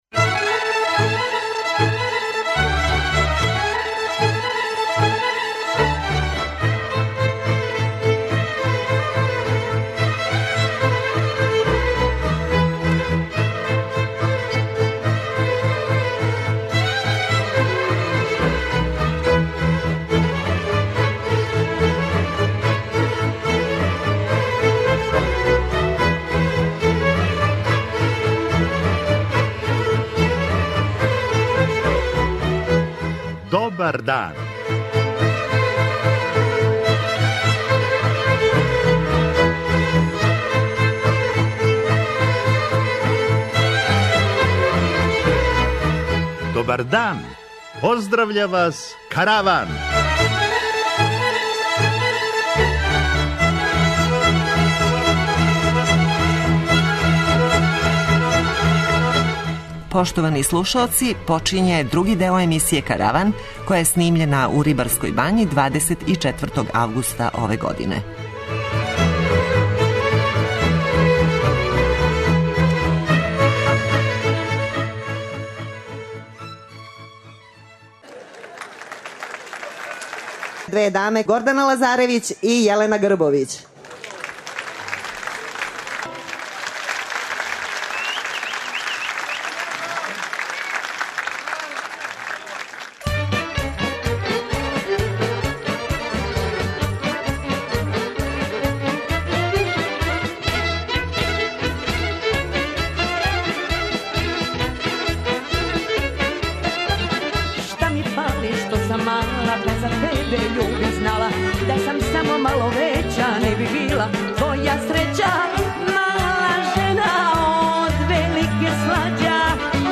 Данас слушате други део емисије који је снимљен у Рибарској бањи.